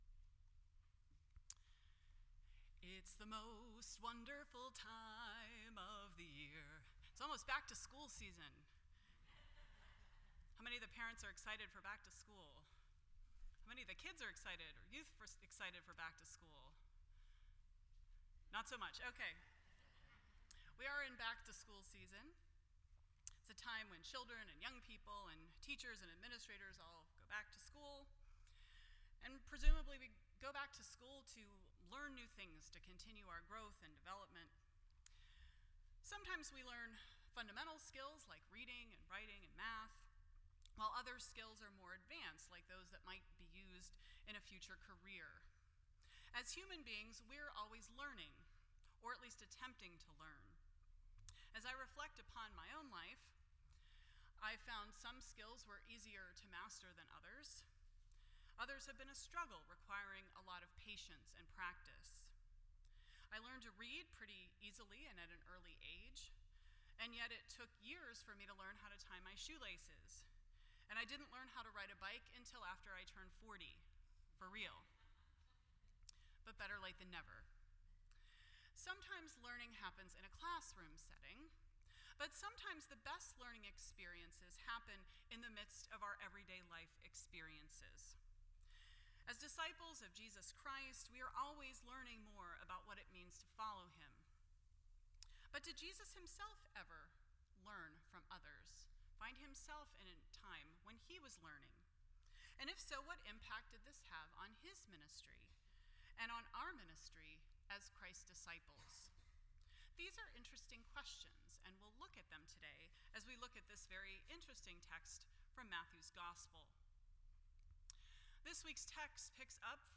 None Service Type: Sunday Morning %todo_render% Share This Story